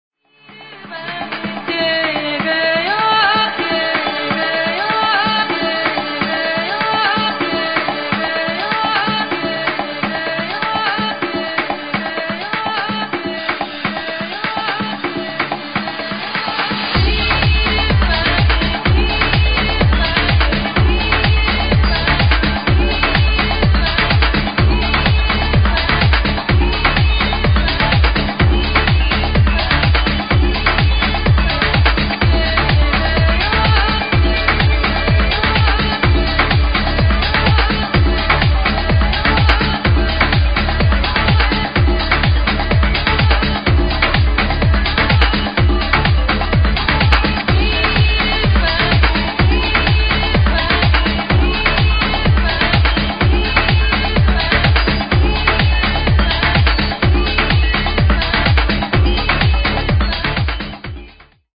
VERY GOOD vocal progressive track
more like a house tune...no idea, ill keep looking, what language is that damn